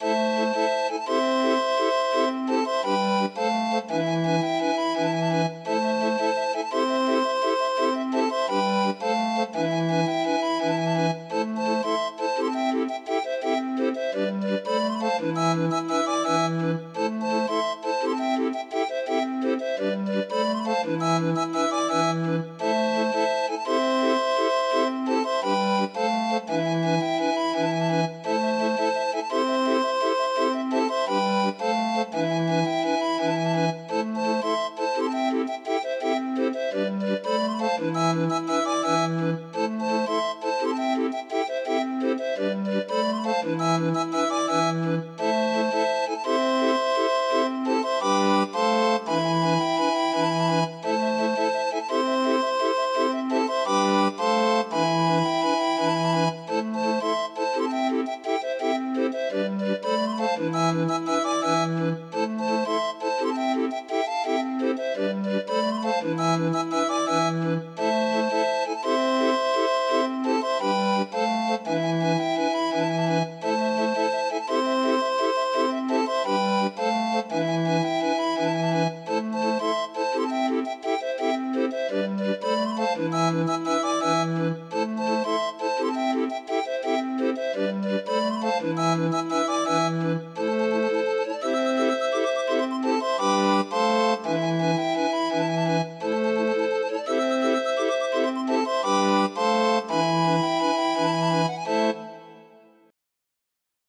Demo of 25 note MIDI file